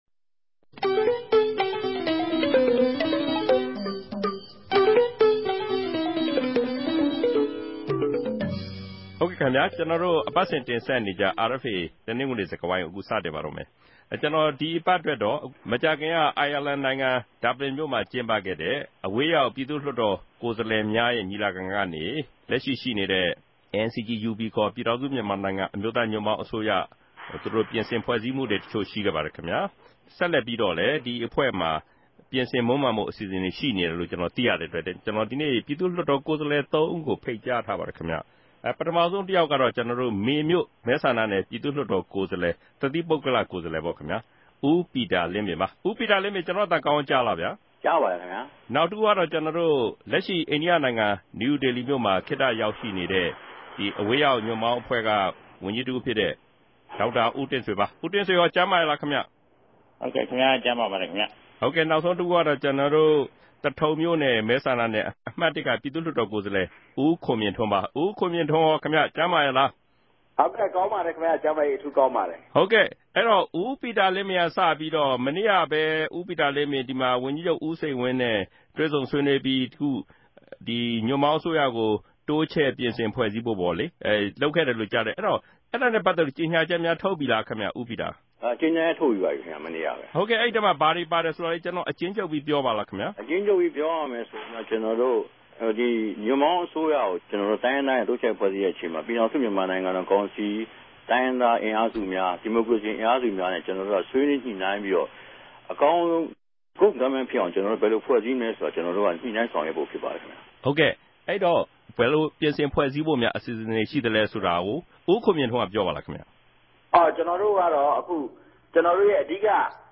တနဂဿေိံြ စကားဝိုင်း။